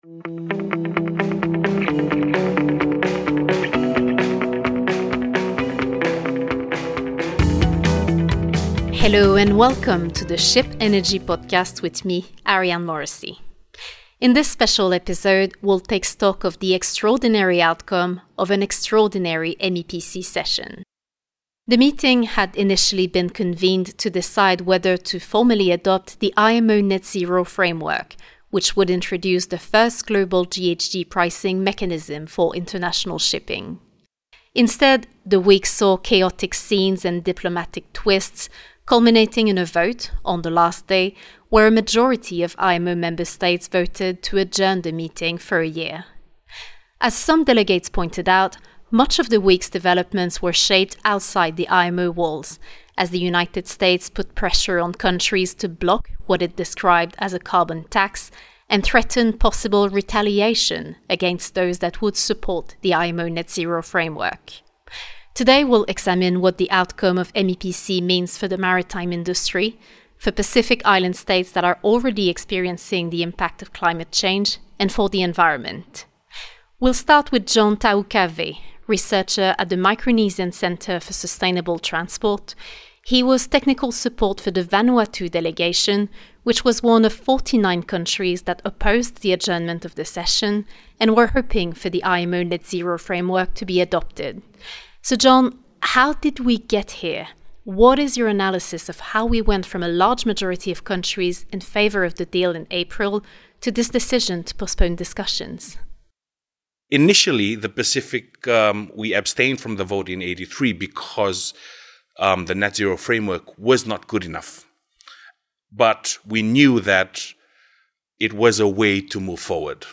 In this special episode, we analyse the implications of the IMO Net-Zero Framework postponement with representatives of the shipping industry, Pacific Island States and environmental NGOs who…